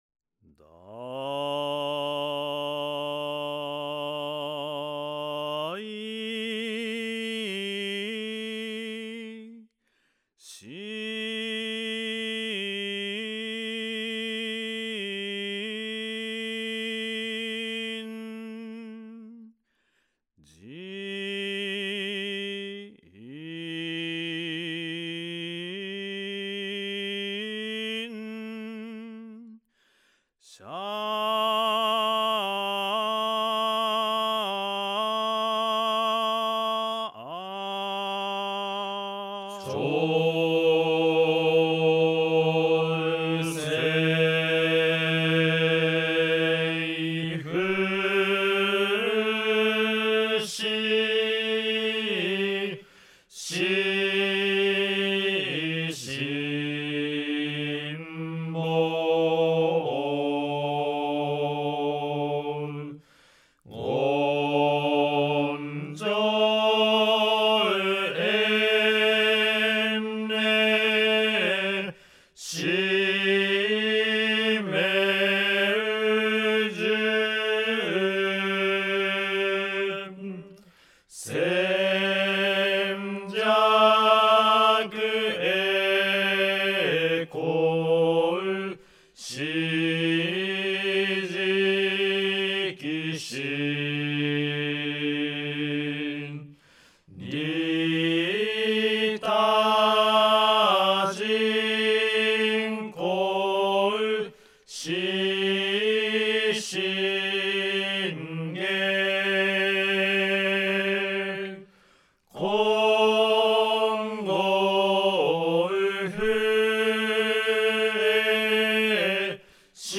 宗祖親鸞聖人によってお念仏のみ教えの真意が開顕された浄土真宗の根本聖典｢顕浄土真実教行証文類｣（教行信証）からすべて御文を選定し、伝統的な声明と大衆唱和の両面を兼ね備えたものとなっています。
また、多くの作法が高音からはじまるものでありますが、聴く・唱えるという視点も重視して、より唱和しやすいものとなるよう、全体的に中低音から次第に高音域に至る採譜が施されています。